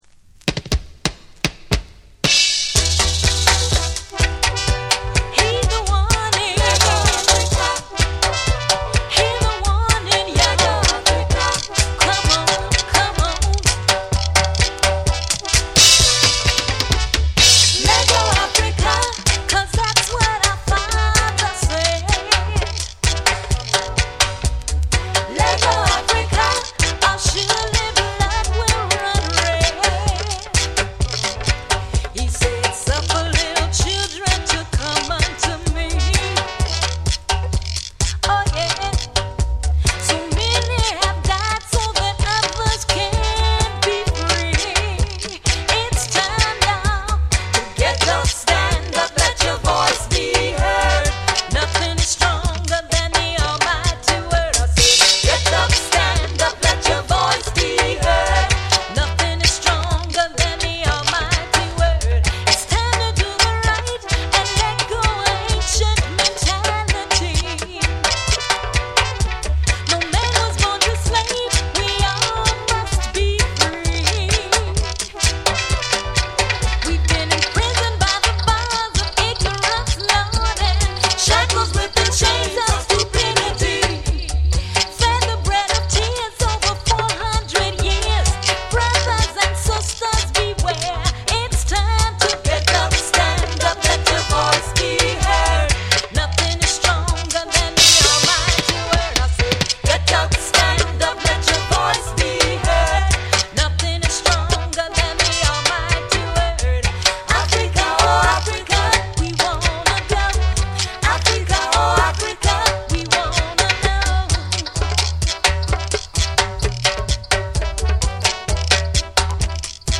温かく力強いヴォーカルと深いグルーヴが融合し、心を揺さぶるレゲエを収録。
REGGAE & DUB